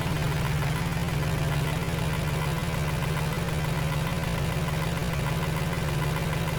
boatengine_idle.wav